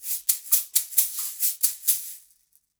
Index of /90_sSampleCDs/USB Soundscan vol.36 - Percussion Loops [AKAI] 1CD/Partition B/22-130SHAKER